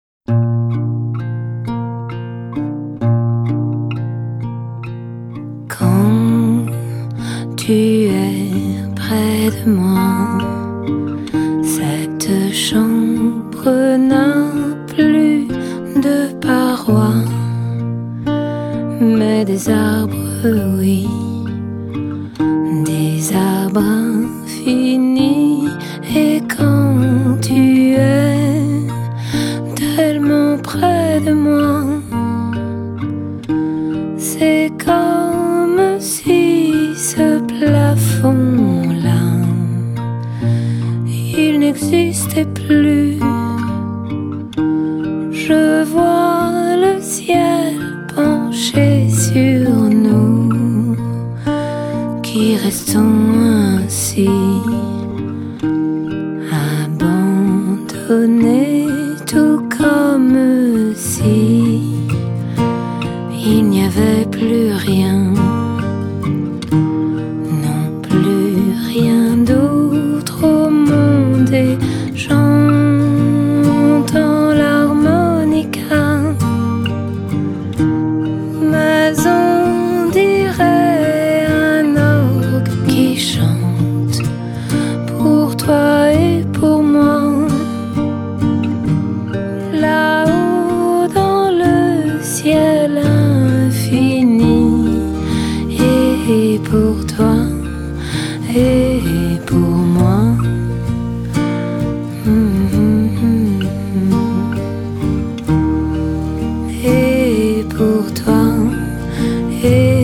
★ 全片錄音的厚度、透明度、層次感與質感皆達示範級效果。
民謠和香頌以完美比例調和
基本上這是一張專輯，除了歌曲迷人動聽之外，音質極佳更加深了音樂本身的感染力。